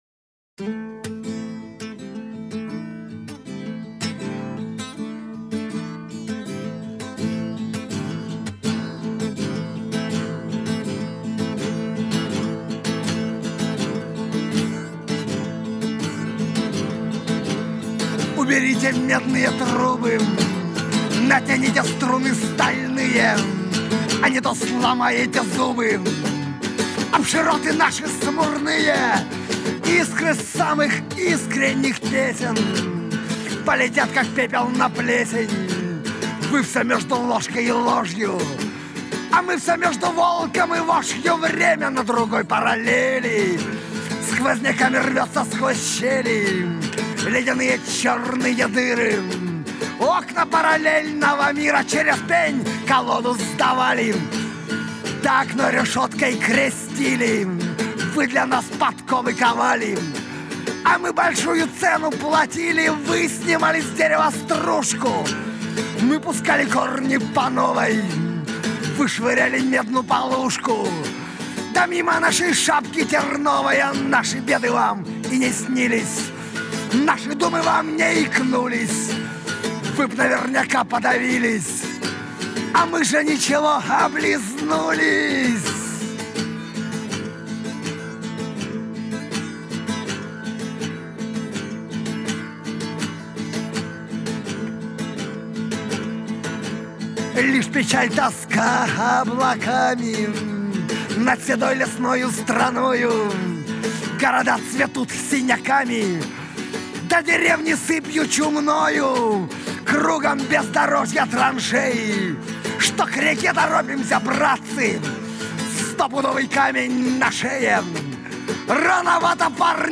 Русский рок Авторские песни